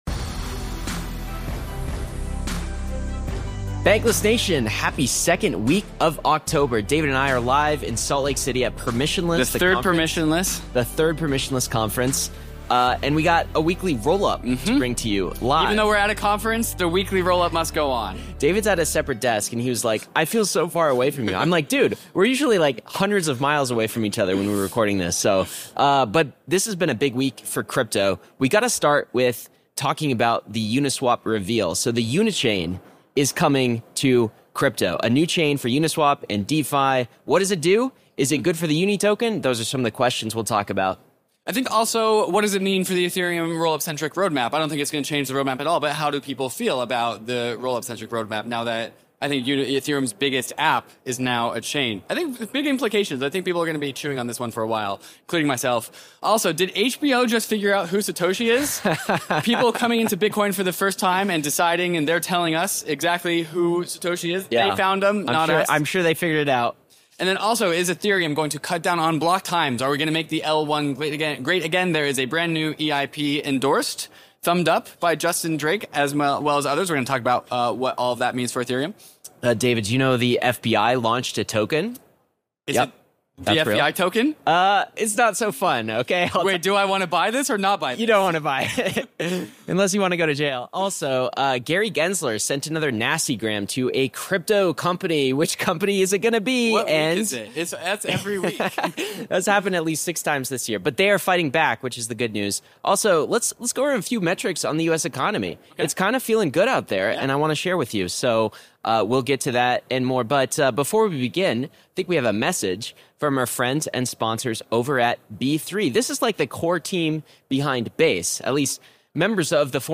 This Weekly Rollup was recorded IRL at Permissionless III.